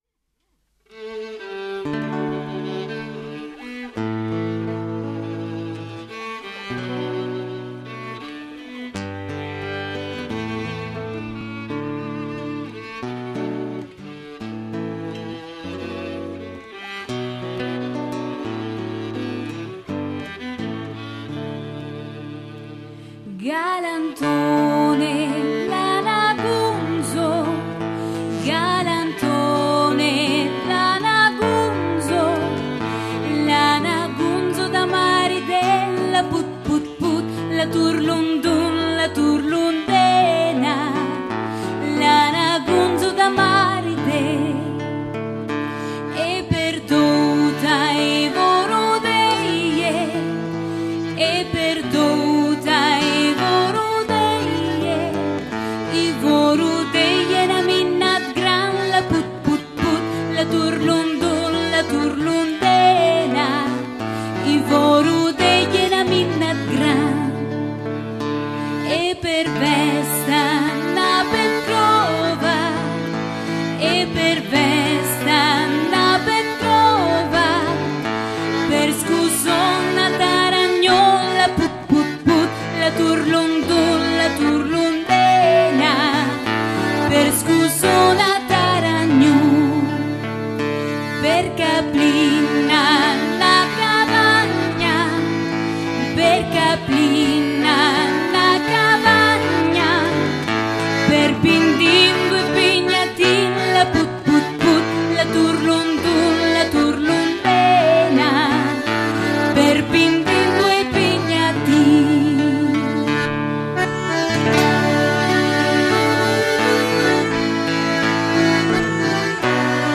MÚSICAS MEDIOEVALI Y CELTA